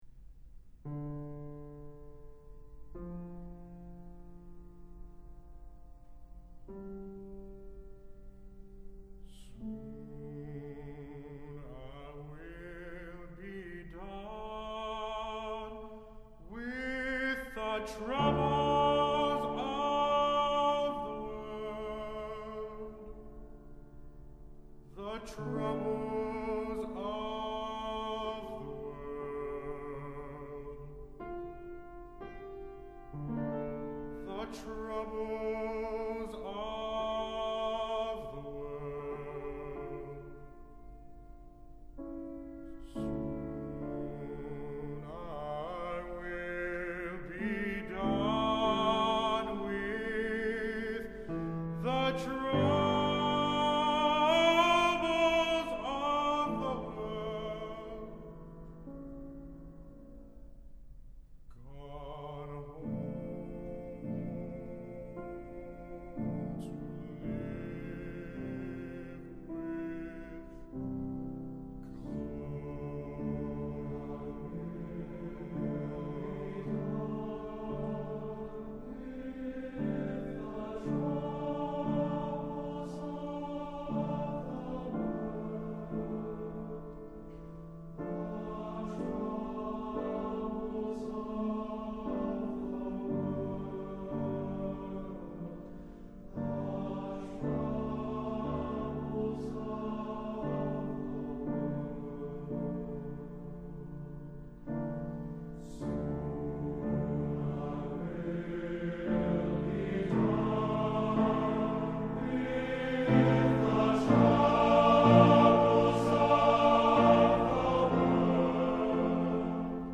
Accompaniment:      Piano, Solo
Music Category:      Choral